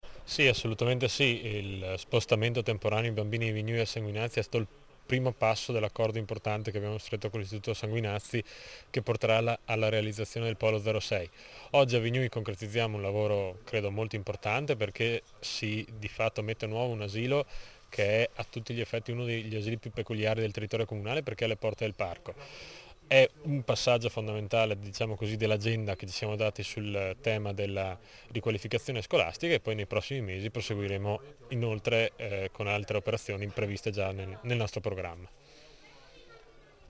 ALESSANDRO DEL BIANCO, ASSESSORE ALL’ISTRUZIONE DEL COMUNE DI FELTRE